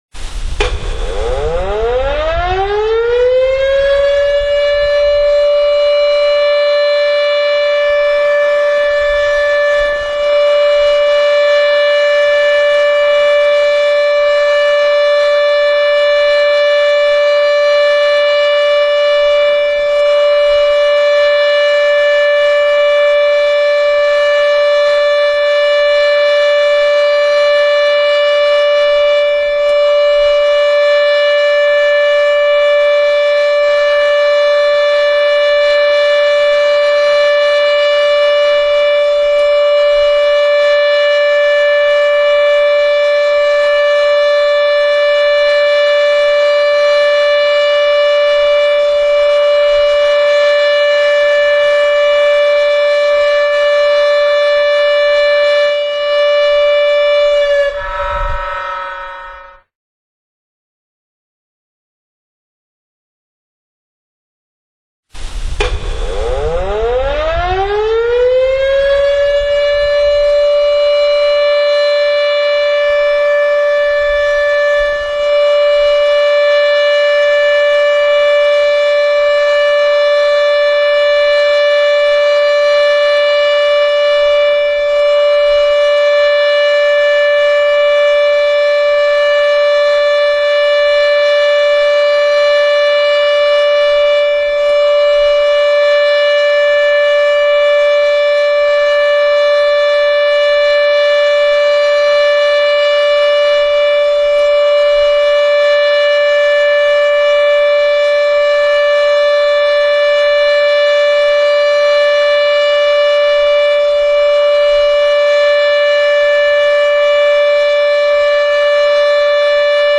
サイレン信号の種類
必要と認める区域内の居住者に避難のため立ち退くべきことを知らせます。   60秒－休止5秒－60秒